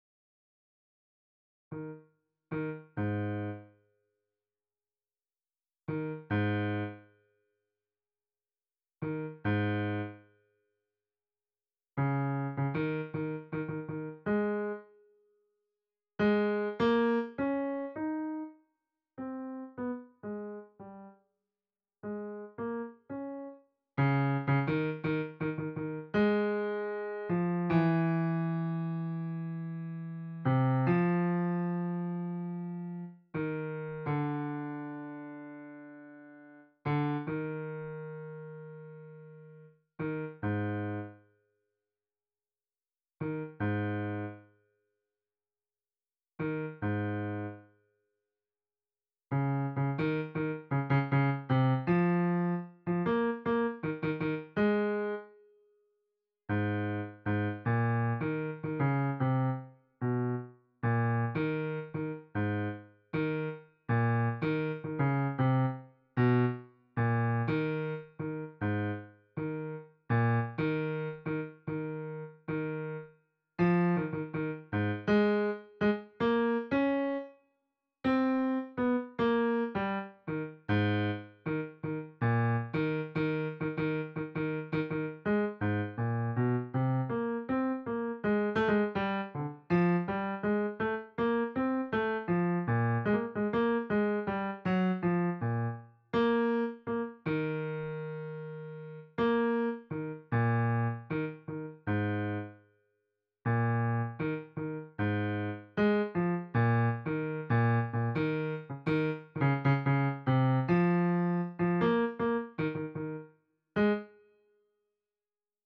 Øvefil for bass (wav)
I got rhythm ~ bass.WAV